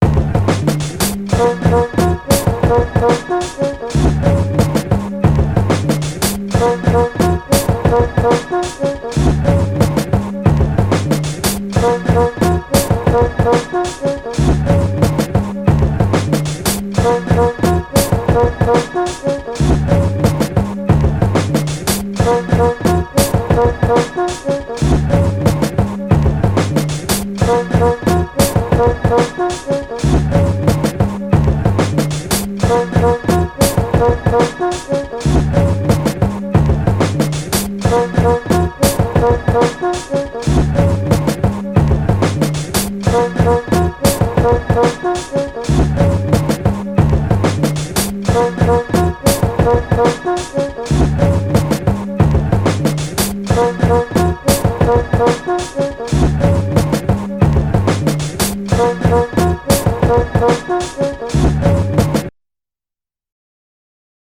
Ho fatto un altro abbozzo di base rap.
Come gli antichi avevano scoperto l'amen break, io l'ho riscoperto anche in brani di Modugno.
A mio avviso dovrebbe essere leggermente rallentata e potenziata sui bassi.